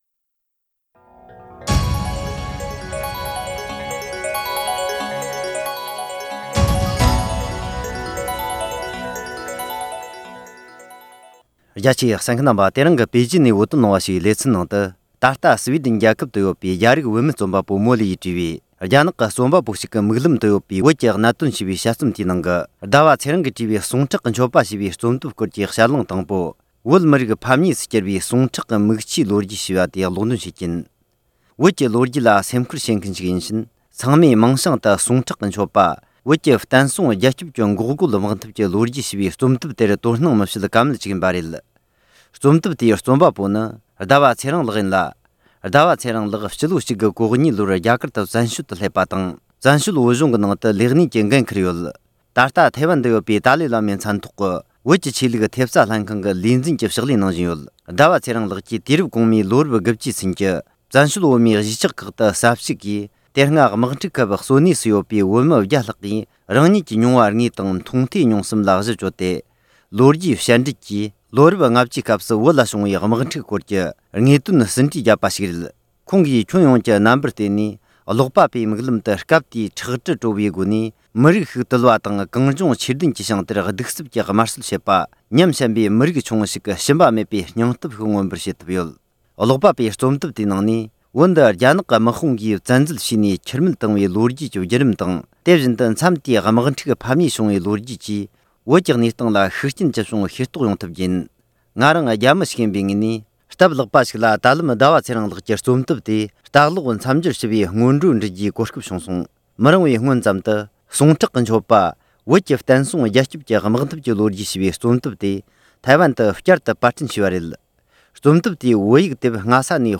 ཐེ་ཝན་སྐུ་ཚབ་དོན་གཅོད་ཟླ་བ་ཚེ་རིང་ལགས་ཀྱིས་བྲིས་པའི་ཟུང་ཁྲག་གི་མཆོད་པ་ཞེས་པའི་ཕྱག་དེབ་ཀྱི་ལེ་ཚན་ལ་གསན་རོགས།